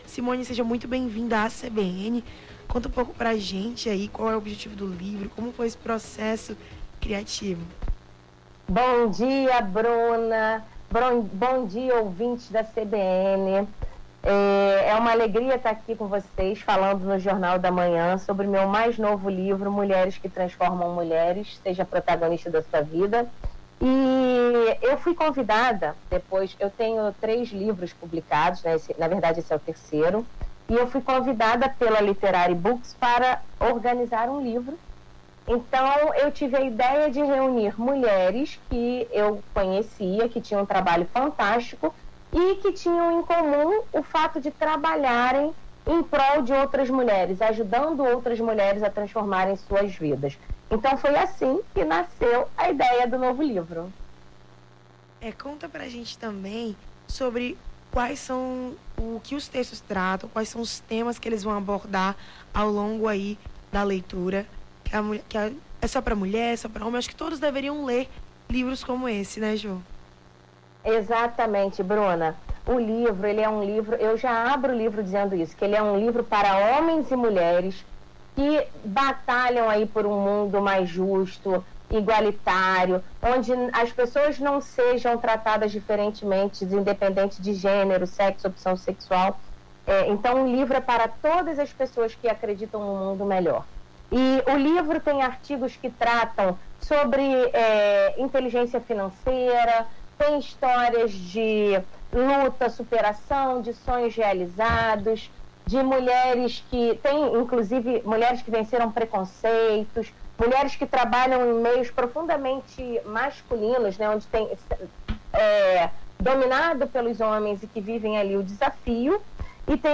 Nome do Artista - CENSURA - ENTREVISTA (MULHERES QUE TRANSFORMAM) 09-03-23.mp3